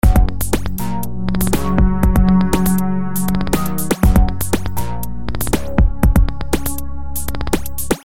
标签： 120 bpm Breakbeat Loops Groove Loops 1.35 MB wav Key : Unknown
声道立体声